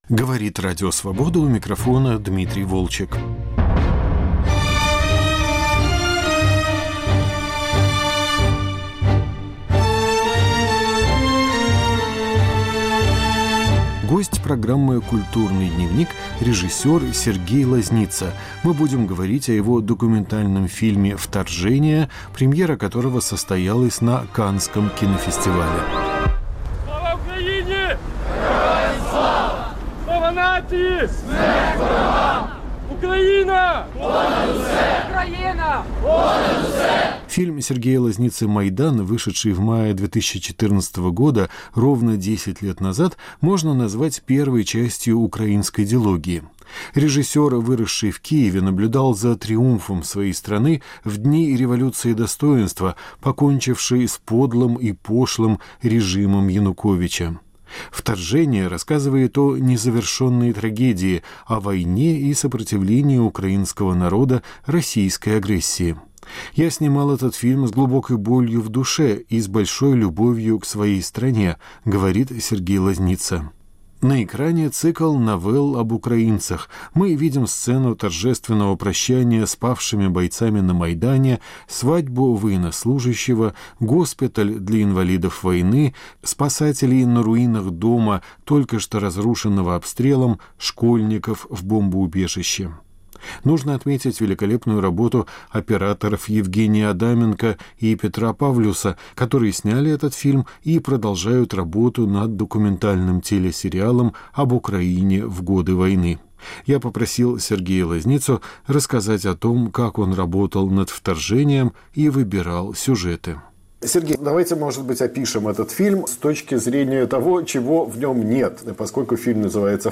Разговор с режиссером о его новом фильме после премьеры на Каннском кинофестивале